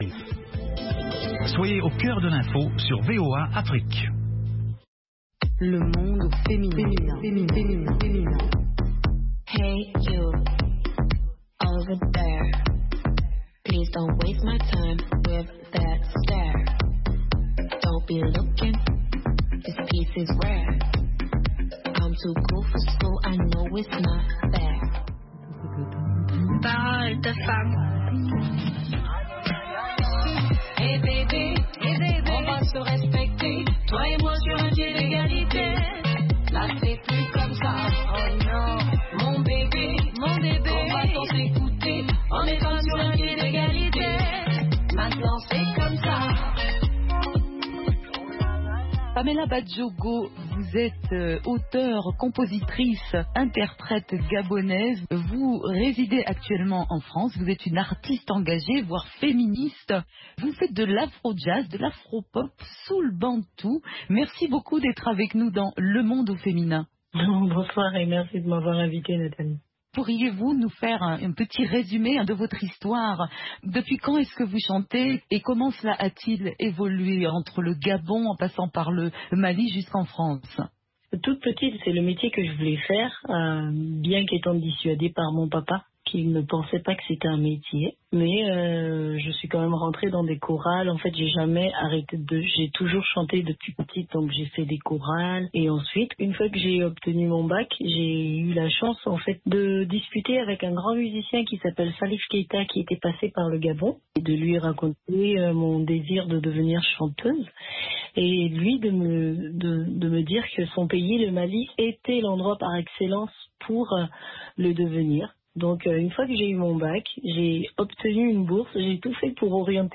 Le Monde au Féminin : entretien musical